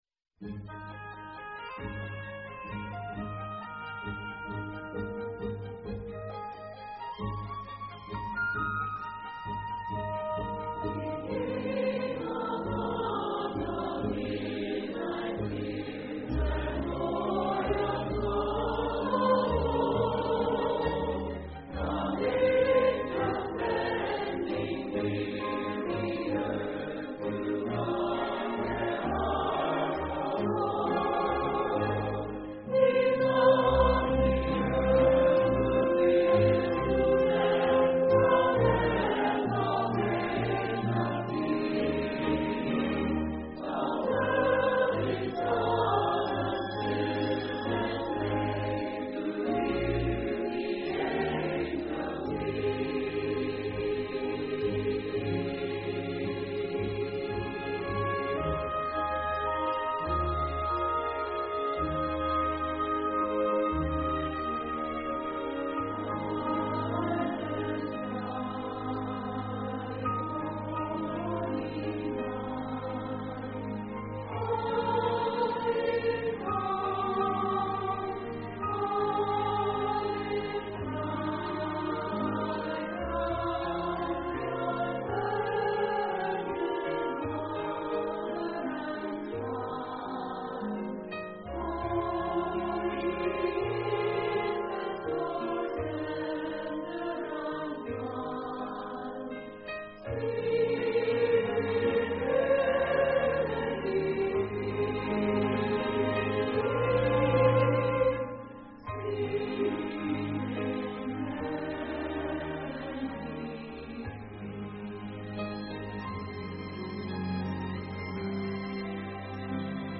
CHRISTMAS / HOLIDAY